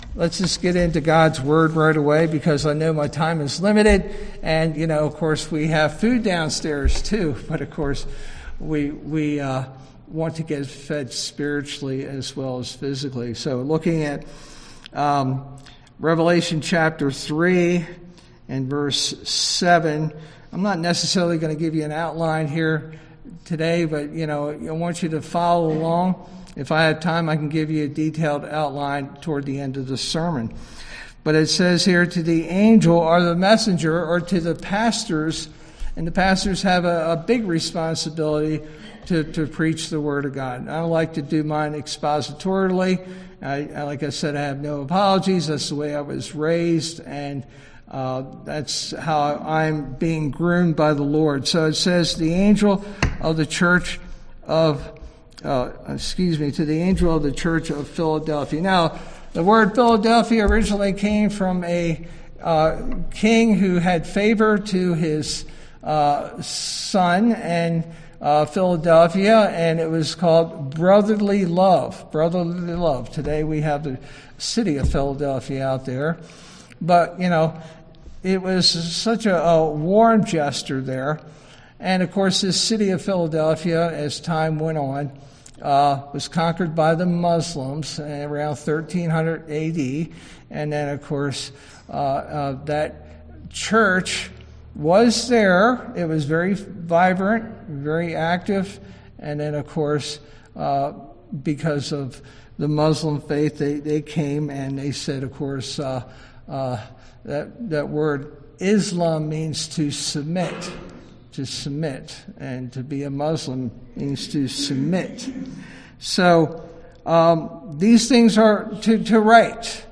Sermon verse: Revelation 3:7-13